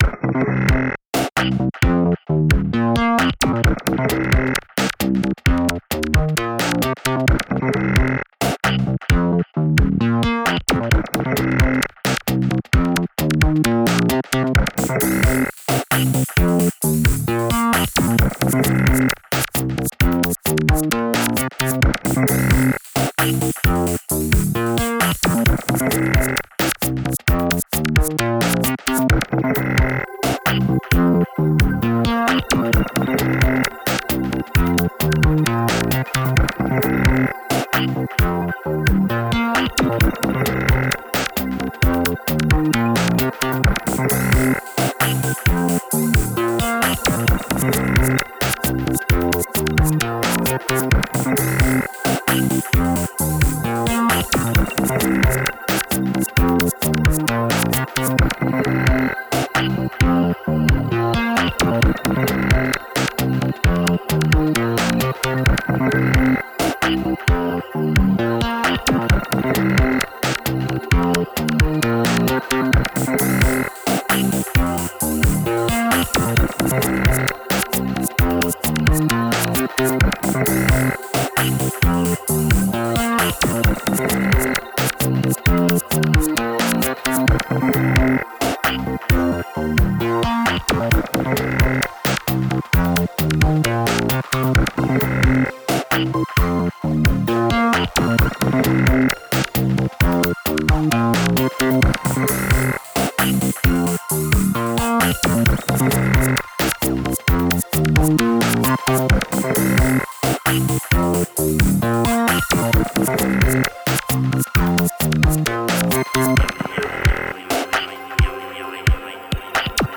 Genre: IDM, Eexperimental.